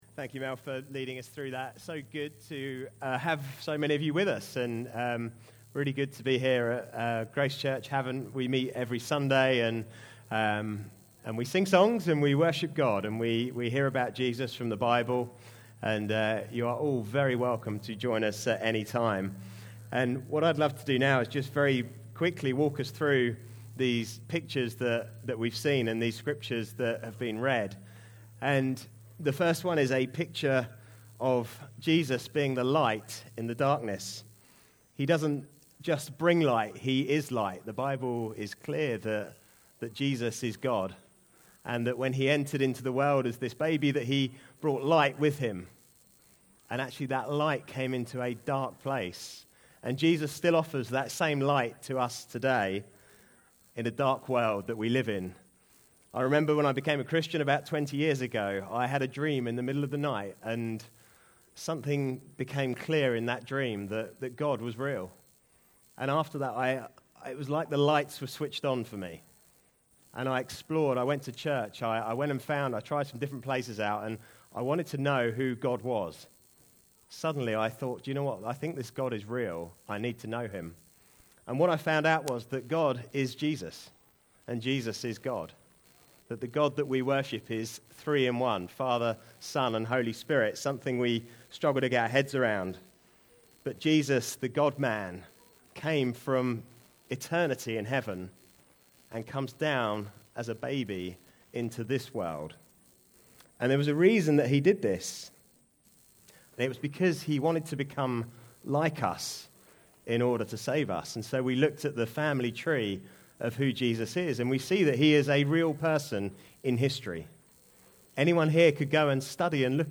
Carol Service 2024 (Havant)